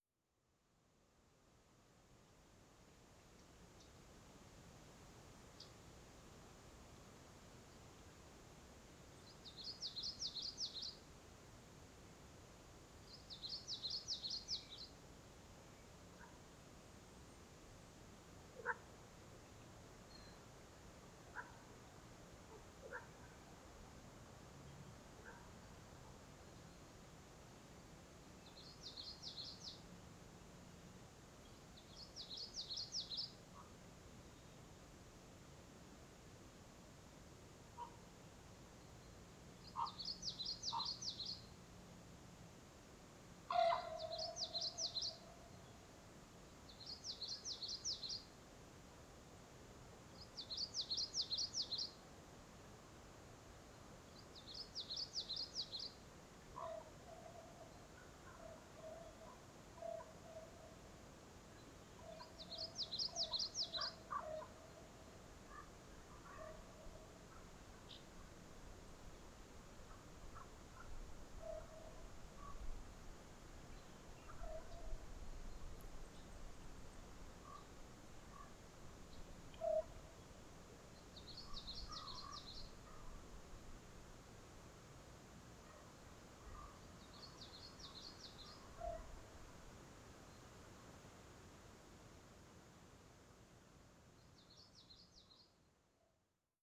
Recordings from the trail through the peaceful second growth forest at Hemer Provincial Park in early spring 2022.
1. Bird sounds – Common Yellowthroat, Common Raven, Red-winged Blackbird (faintly)